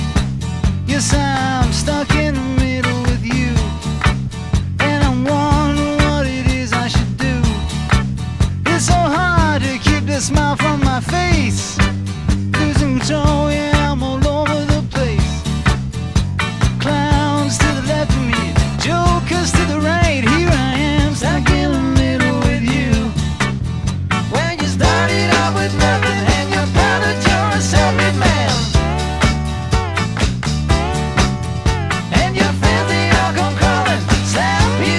Folk-Rock
Soft Rock
Жанр: Поп музыка / Рок / Фолк